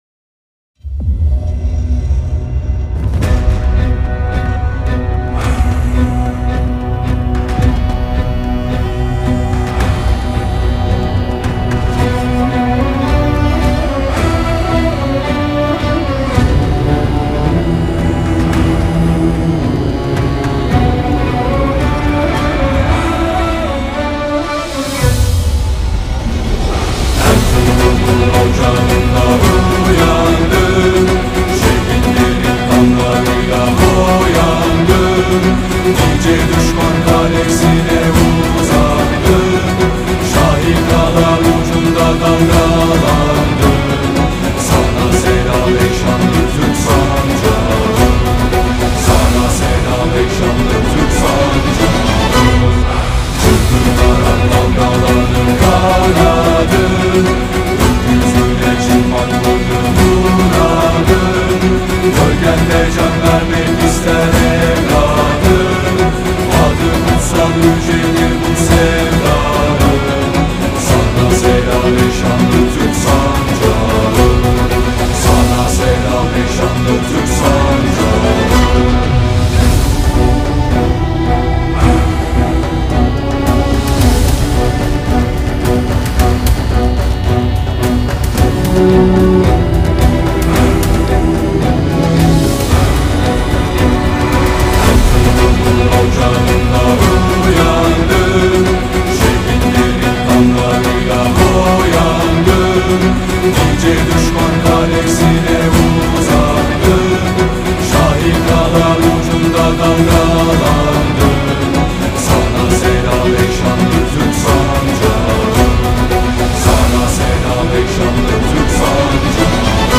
tema dizi müziği, heyecan aksiyon enerjik fon müziği.